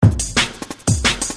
Tag: 125 bpm Techno Loops Drum Loops 330.79 KB wav Key : Unknown